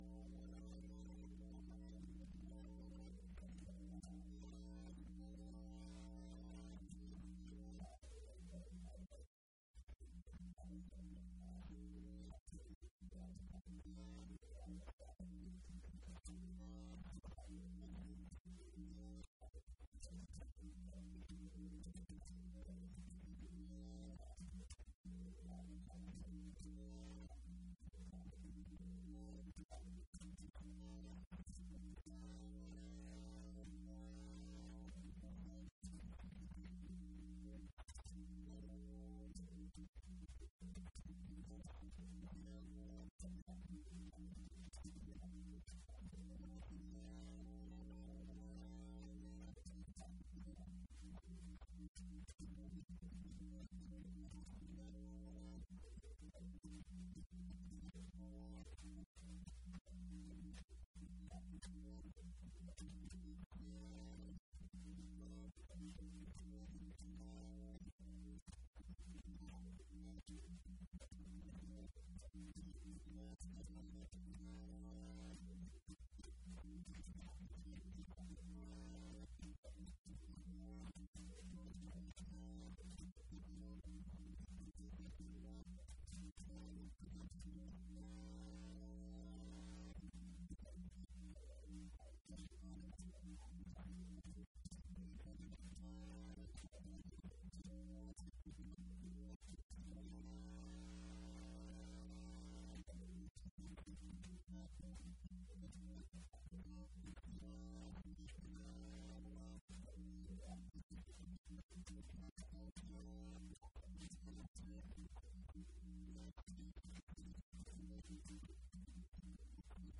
Event details for Paul Gl. Allen School distinquished lectures, colloquium, dissertations defense and more.